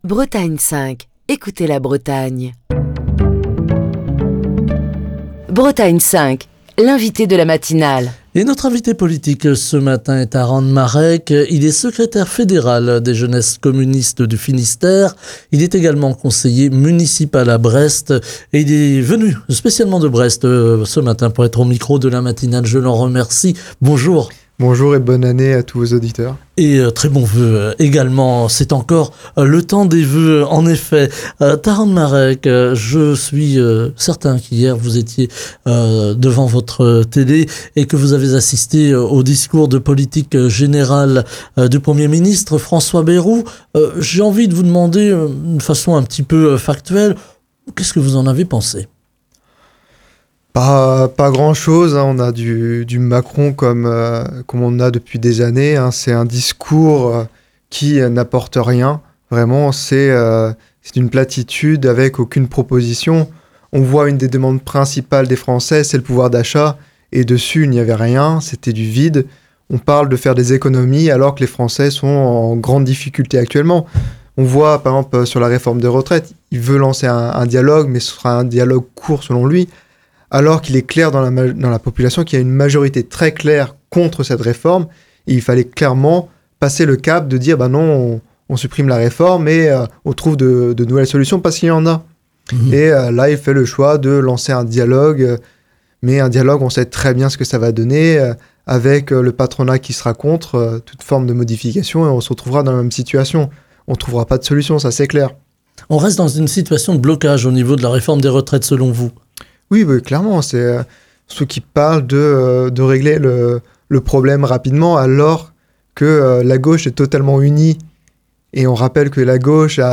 Taran Marec, secrétaire fédéral des Jeunes communistes du Finistère, conseiller municipal à Brest était l'invité politique de la matinale de Bretagne 5 ce mercredi. Il a réagi à la déclaration de politique générale prononcée hier à l'Assemblée nationale par le Premier ministre François Bayrou, et s'est dit peu convaincu par les orientations budgétaires annoncées, les jugeant éloignées des préoccupations réelles des Français.